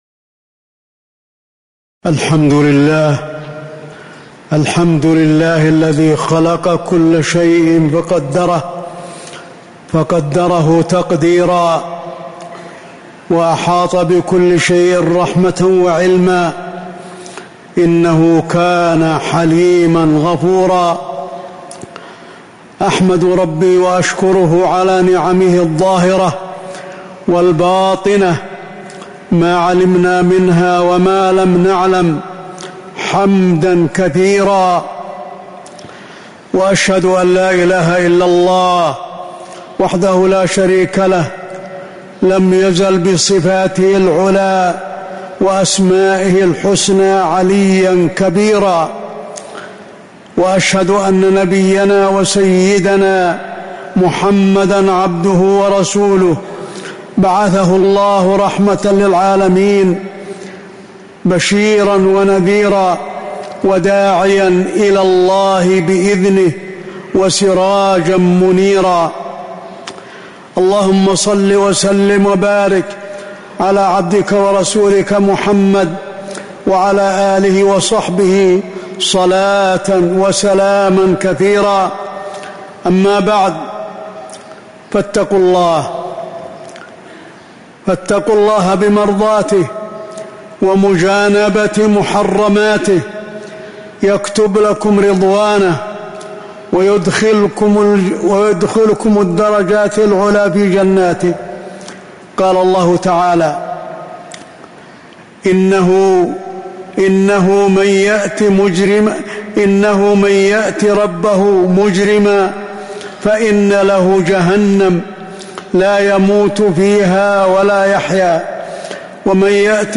تاريخ النشر ١٠ ربيع الأول ١٤٤٦ هـ المكان: المسجد النبوي الشيخ: فضيلة الشيخ د. علي بن عبدالرحمن الحذيفي فضيلة الشيخ د. علي بن عبدالرحمن الحذيفي حال الإنسان بعد موته